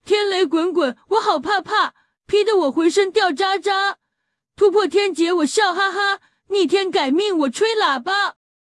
通过不到7s的音频素材进行学习，就可以复刻蜡笔小新的声音，并让其准确说出哪吒的经典打油诗。
这些音频的实现效果，有的仅需要原角色不到5s的音频就能实现，且可以看出上面几段音频都没有出现明显的卡顿，还复刻了声调上扬、语速、说话节奏等细微的特征。